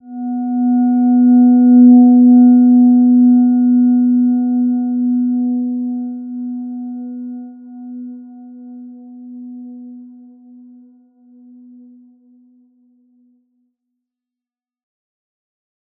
Silver-Gem-B3-mf.wav